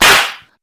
Mario Slap Sound Button - Free Download & Play